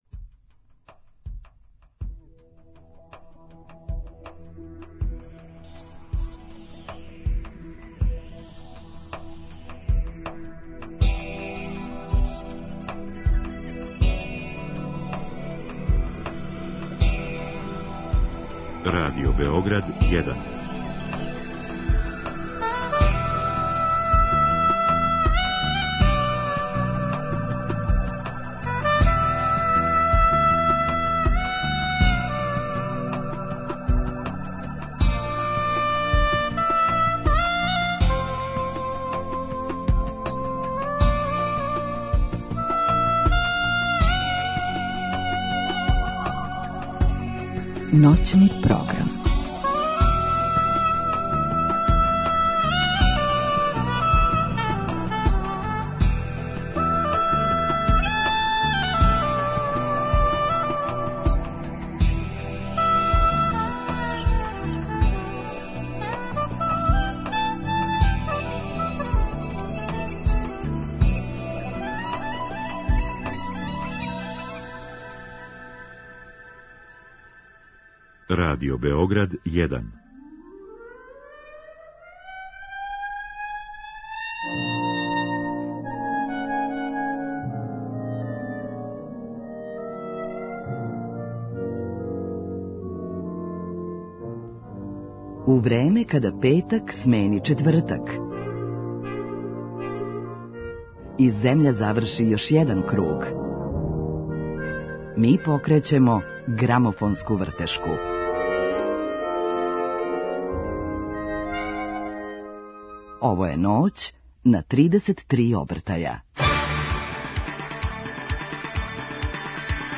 Гост емисије је Миша Алексић, музичар